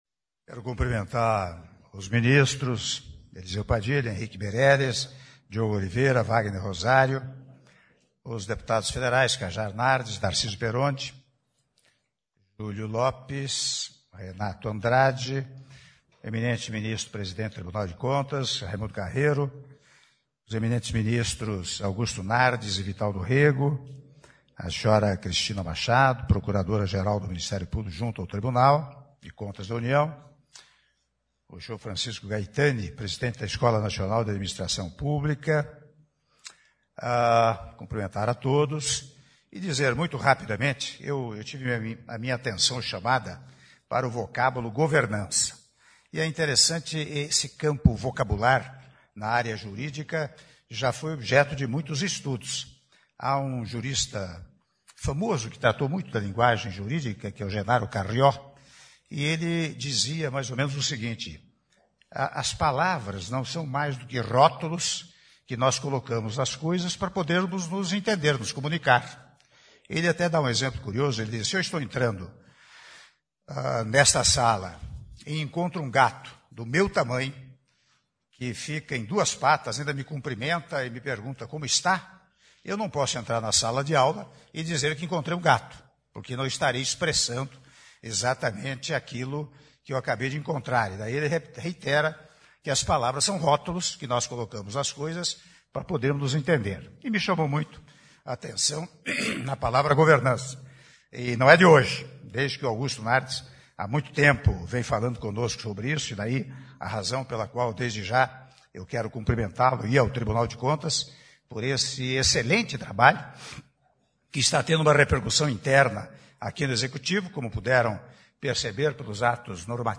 Áudio do discurso do Presidente da República, Michel Temer, durante cerimônia de Anúncio da Política de Governança Pública- Brasília/DF (09min04s)